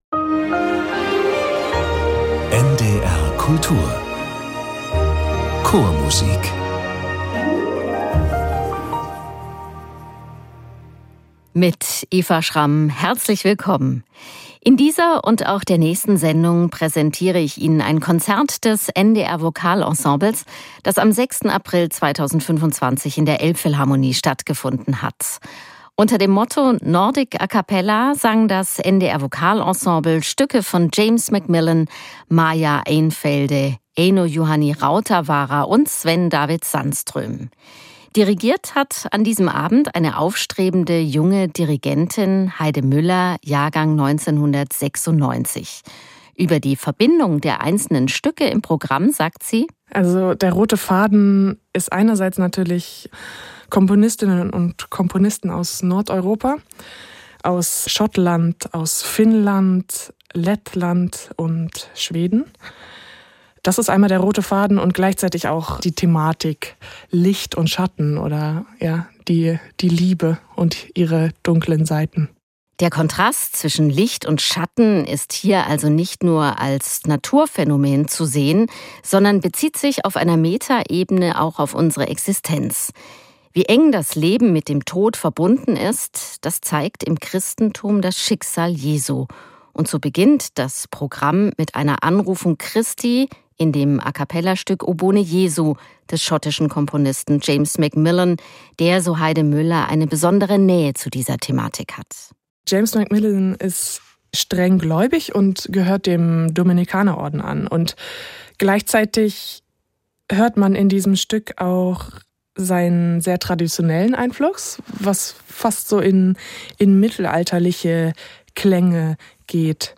Chormusik aus Nordeuropa und dem Baltikum